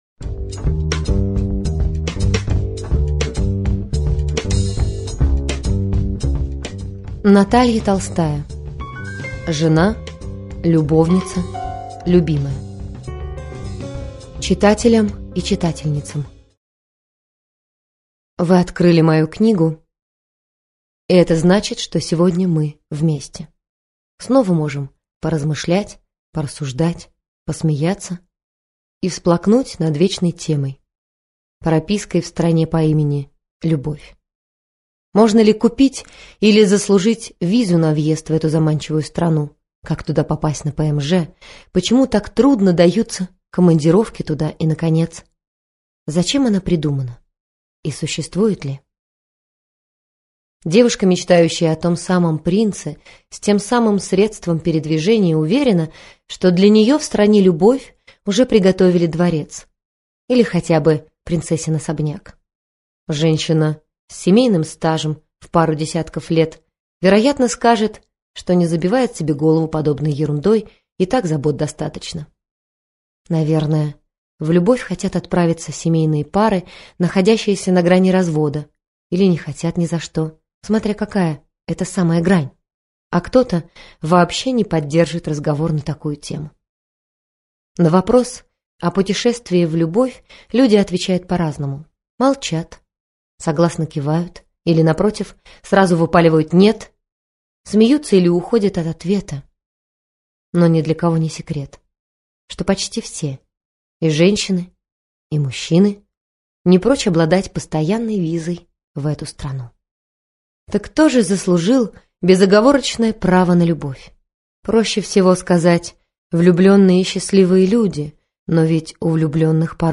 Аудиокнига Жена, любовница, любимая | Библиотека аудиокниг